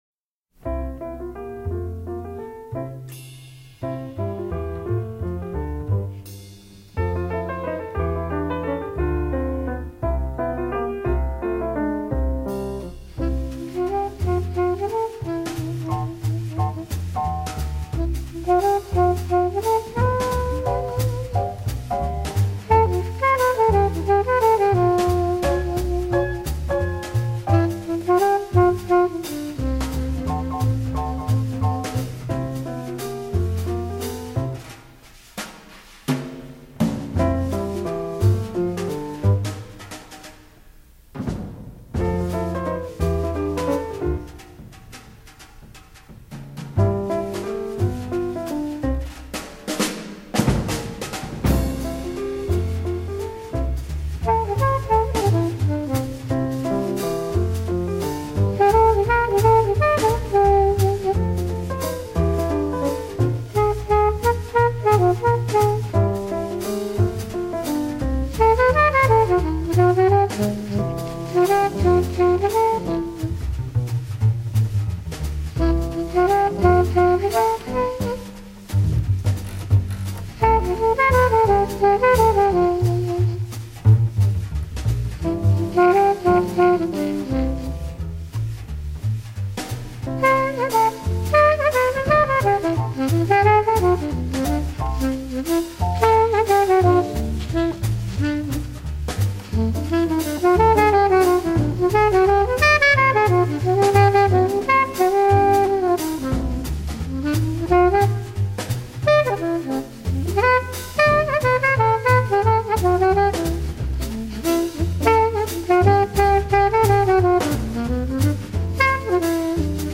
最开始时这首爵士乐以单曲的形式发行，由于采用了极为罕见的5/4拍，